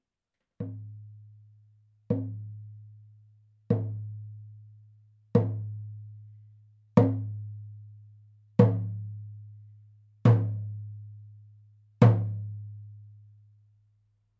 ネイティブ アメリカン（インディアン）ドラム NATIVE AMERICAN (INDIAN) DRUM 14インチ（deer 鹿）
ネイティブアメリカン インディアン ドラムの音を聴く
乾いた張り気味の音です 温度・湿度により皮の張り（音程）が大きく変化します